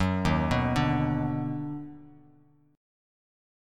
D#mM7#5 chord